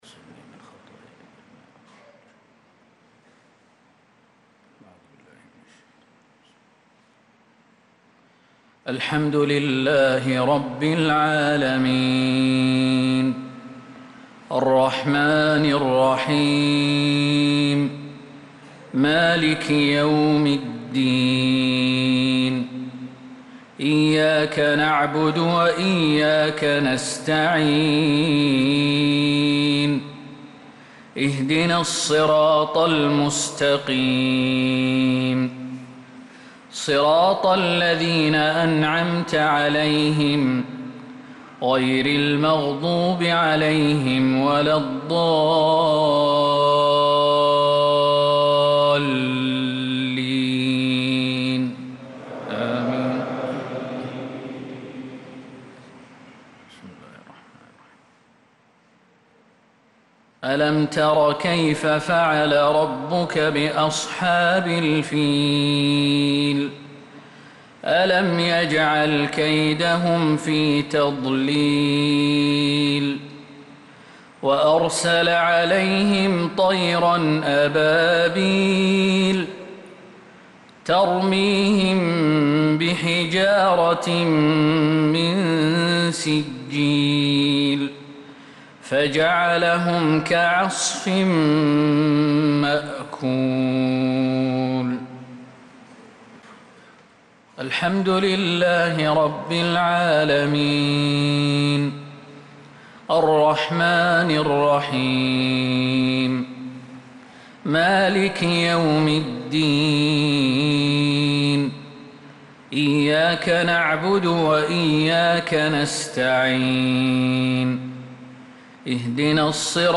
صلاة المغرب للقارئ خالد المهنا 19 محرم 1446 هـ
تِلَاوَات الْحَرَمَيْن .